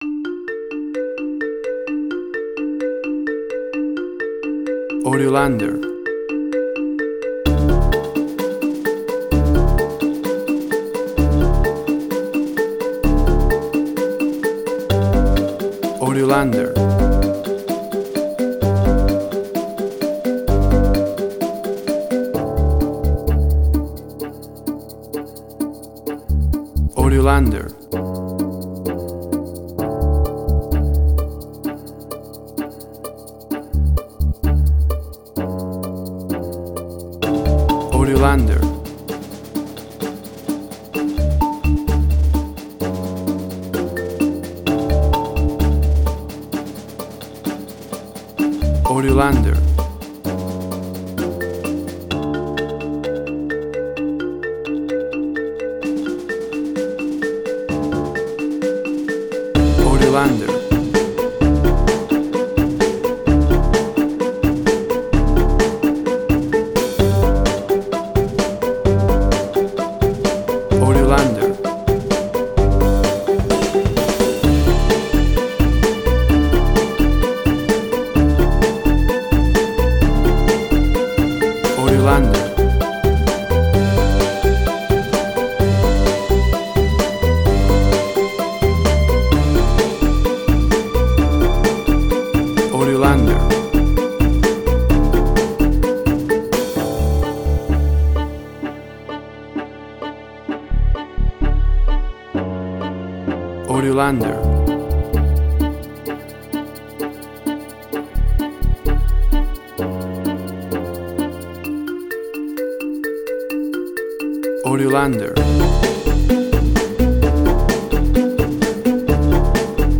Suspense, Drama, Quirky, Emotional.
Tempo (BPM): 130